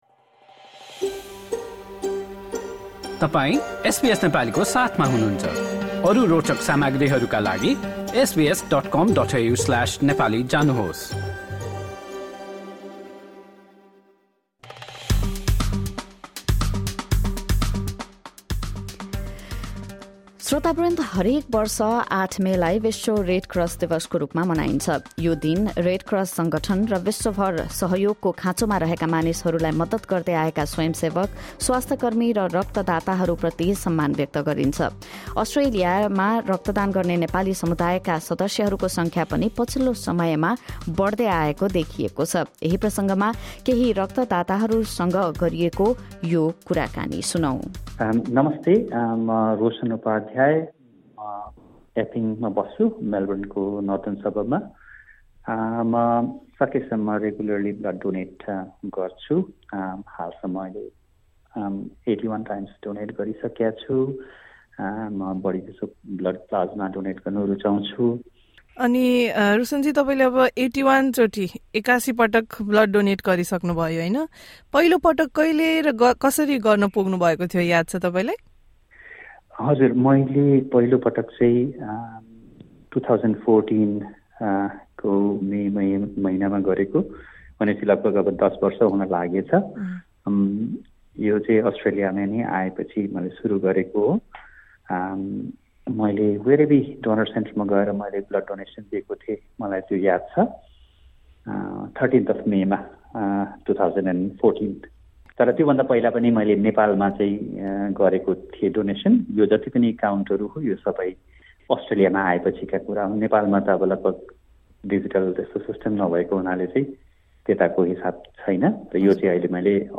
Blood donors from Melbourne spoke to SBS Nepali about their experience in donating blood and the increasing participation from the Nepali community.